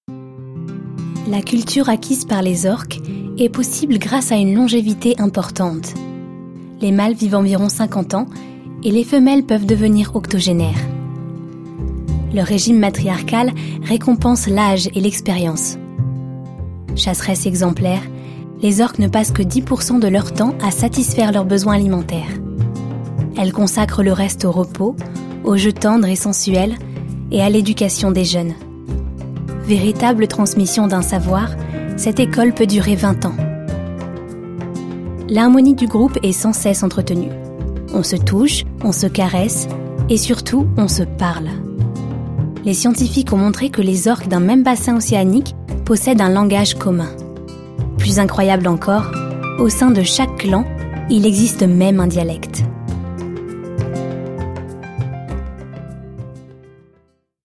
Bandes-son
Narration (démo)
3 - 35 ans - Mezzo-soprano